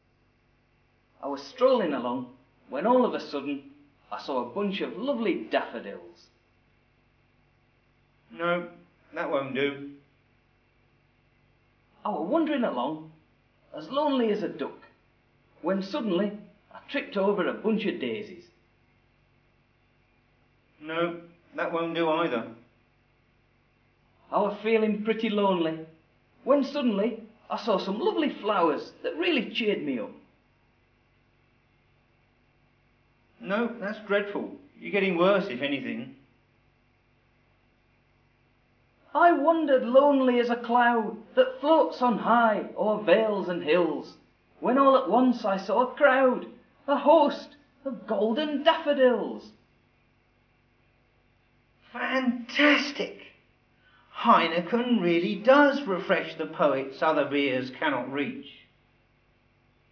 The Heineken Advert